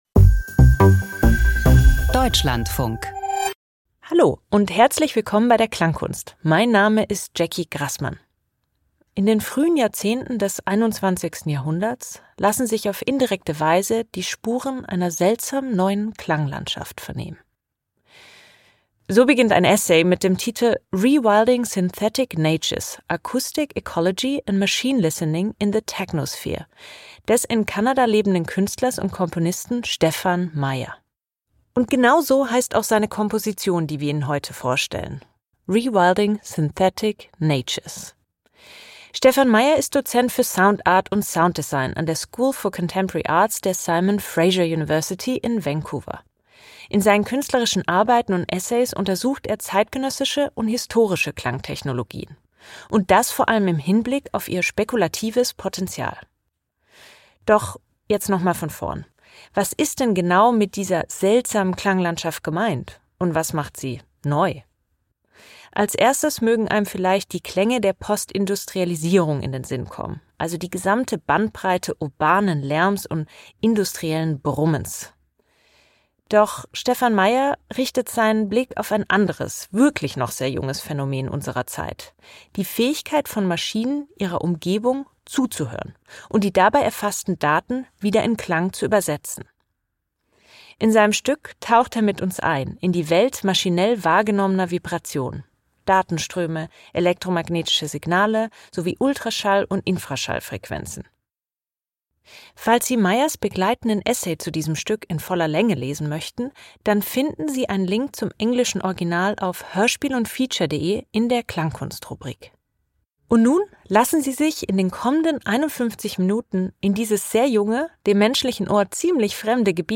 Hörspiel über humane Sterbebegleitung - Intensivstation oder Das unveränderte pflanzenhafte Dahinvegetieren